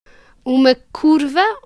prononciation Uma curva ↘ explication “Uma curva” en portugais c’est comme prendre un virage, et Kurwa en polonais c’est une insulte: pute.